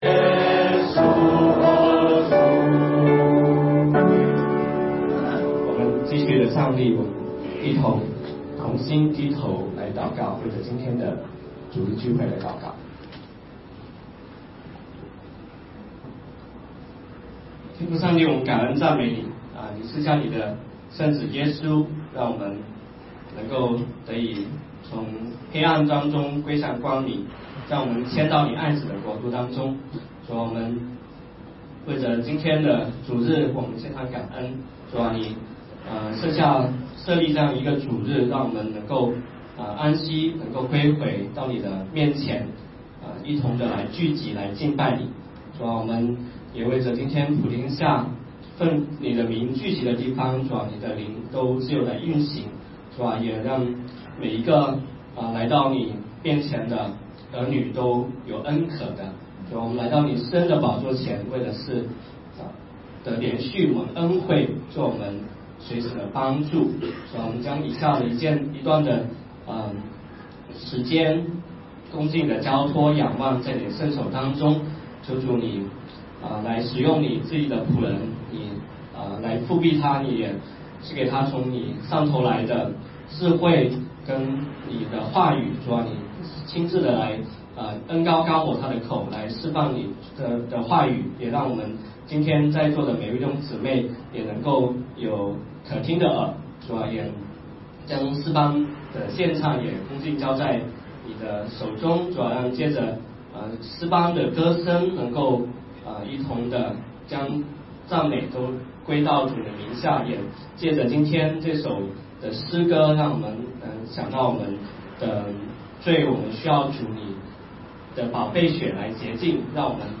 罗马书第7讲 2015年10月18日 下午9:39 作者：admin 分类： 罗马书圣经讲道 阅读(4.53K